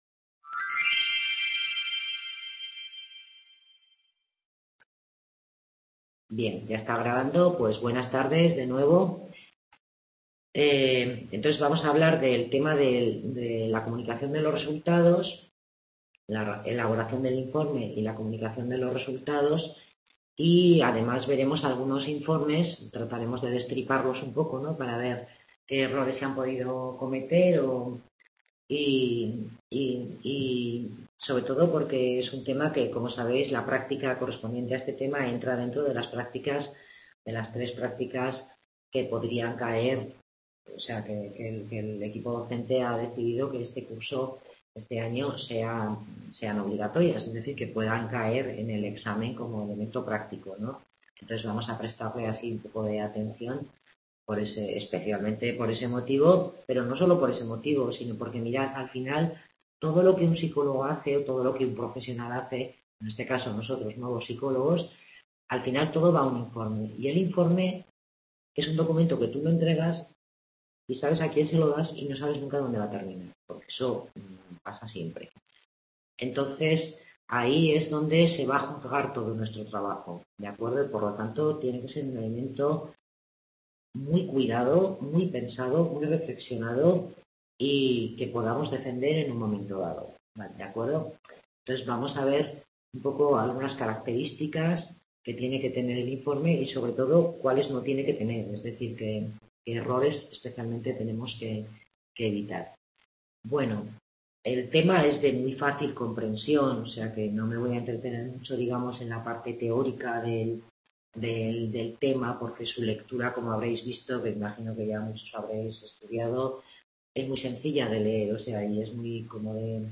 Tutoría grupal sobre el tema de Información y Comunicación de la Evaluación Psicológica.